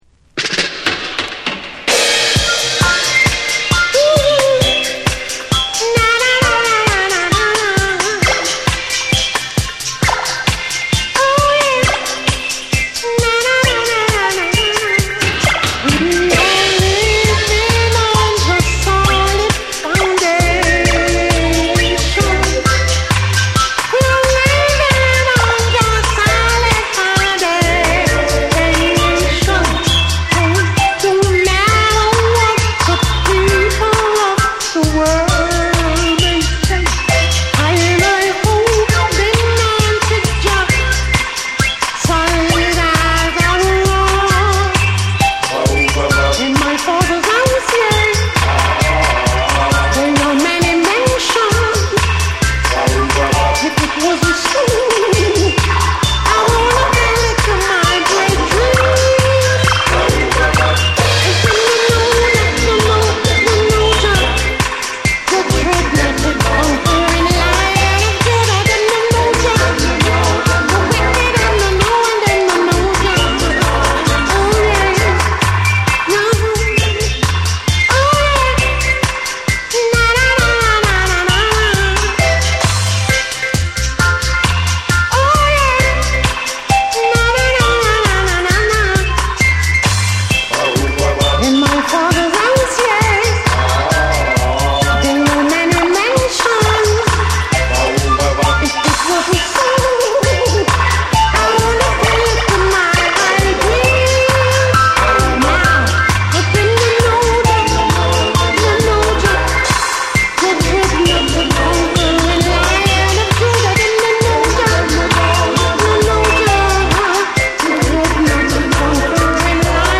名門ルーツ・ヴォーカル・グループ
重厚なベースと空間処理が光る、ルーツ〜ダブ好きに外せない1枚。